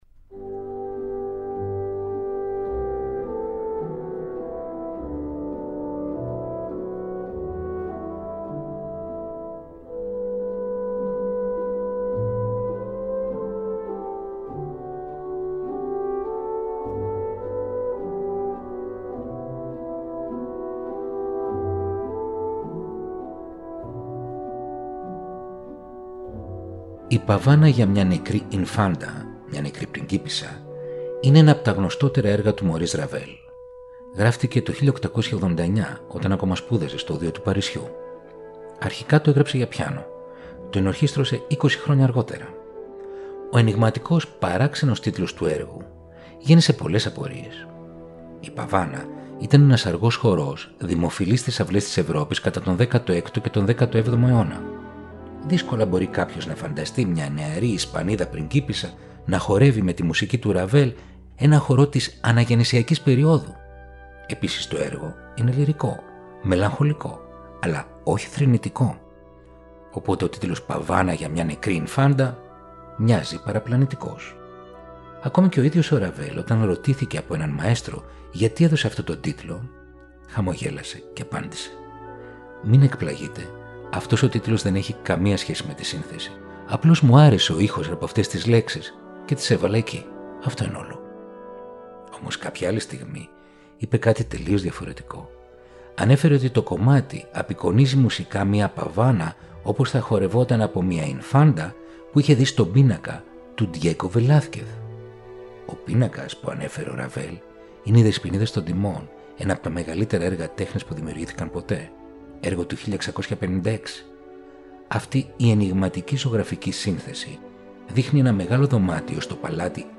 Η τζαζ συναντά την κλασσική μουσική